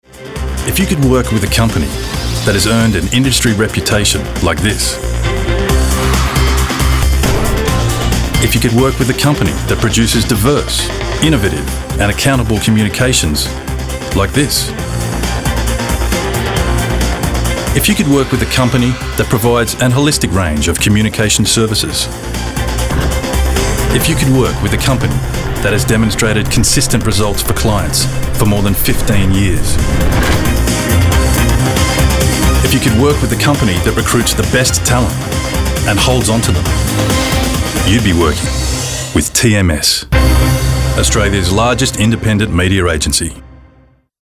Inglês (australiano)
Vídeos Corporativos
BarítonoGravesContraltoProfundoBaixo